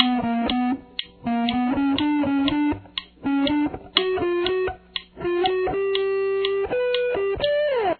Guitar 2